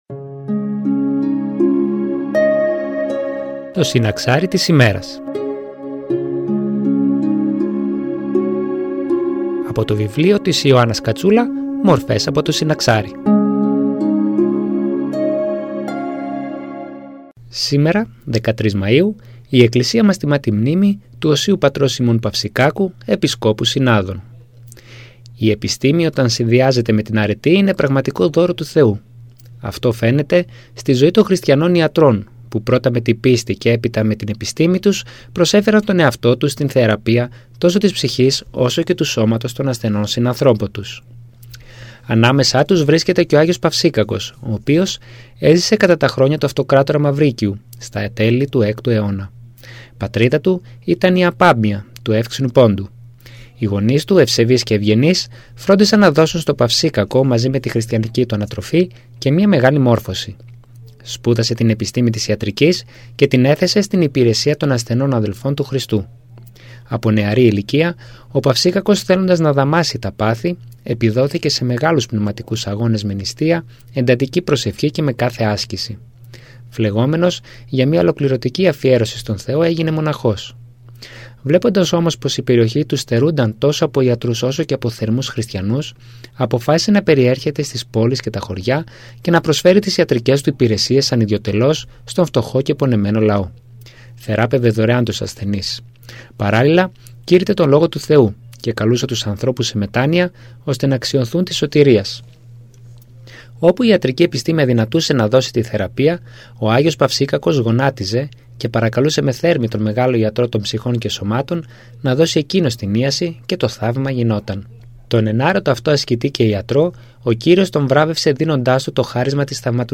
Εκκλησιαστική εκπομπή